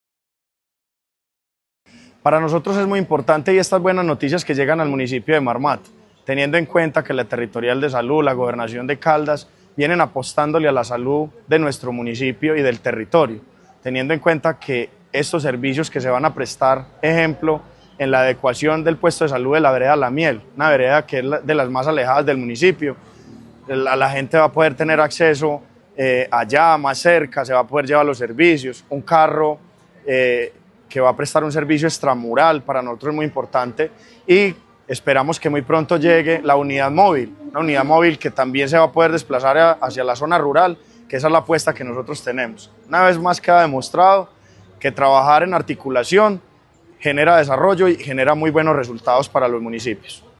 Carlos Alberto Cortés, alcalde de Marmato.